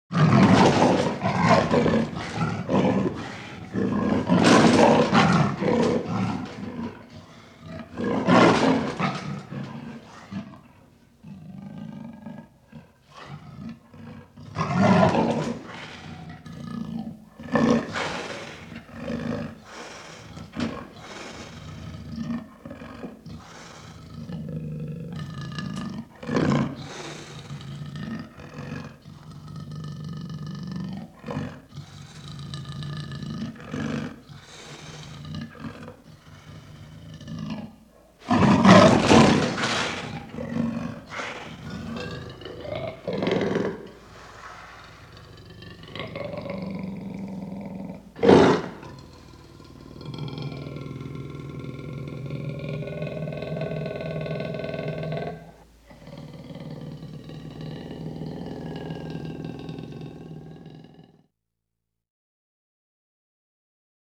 animal
Panther Various Growlings